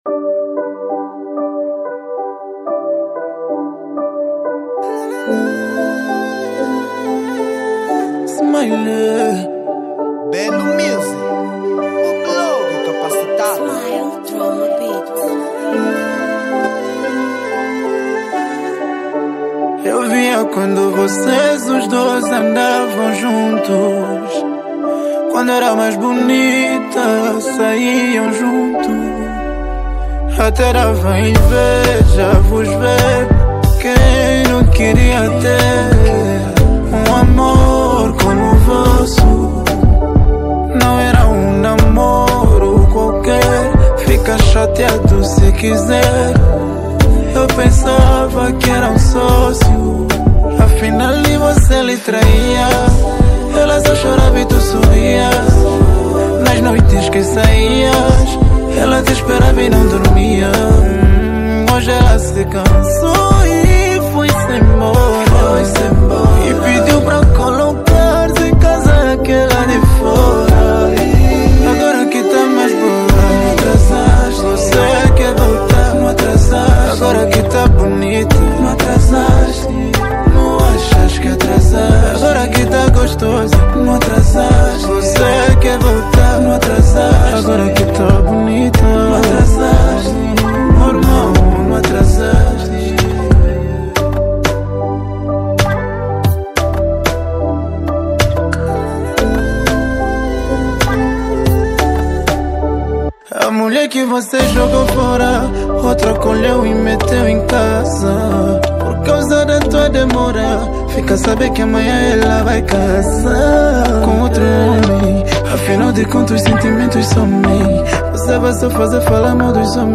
Género: Zouk Fo...